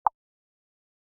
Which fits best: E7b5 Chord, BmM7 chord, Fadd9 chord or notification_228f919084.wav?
notification_228f919084.wav